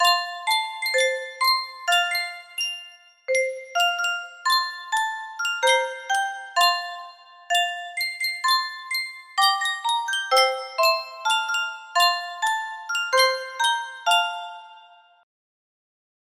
Sankyo Music Box - Day-O The Banana Boat Song LVS music box melody
Full range 60